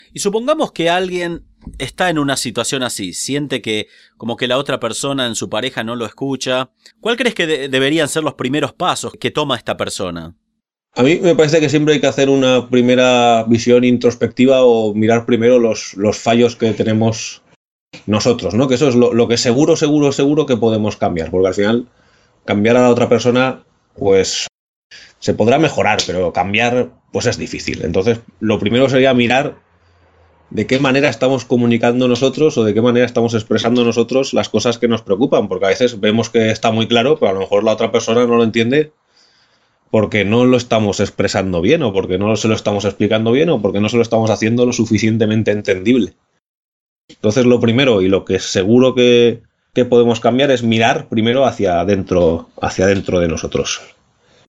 Interview
Recording: 0063 Level: Advanced Spanish Variety: Spanish from Spain